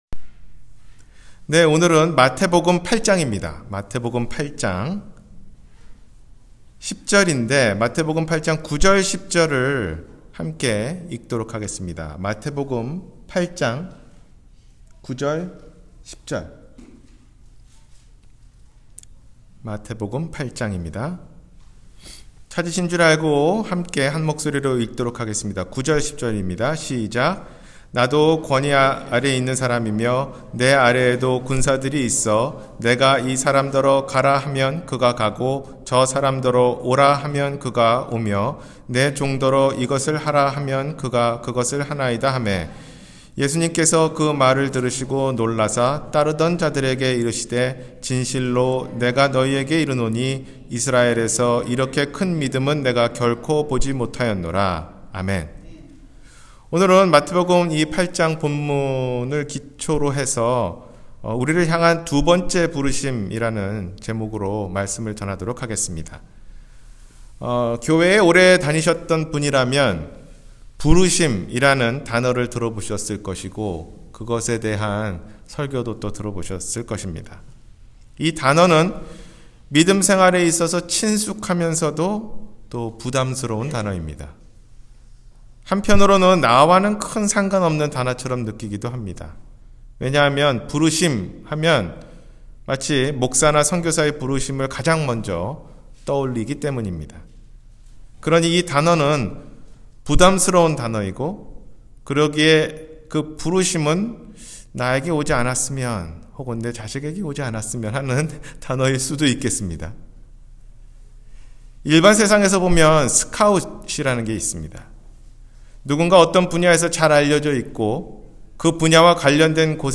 우리를 향한 두번째 부르심 – 주일설교